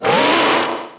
PLA cries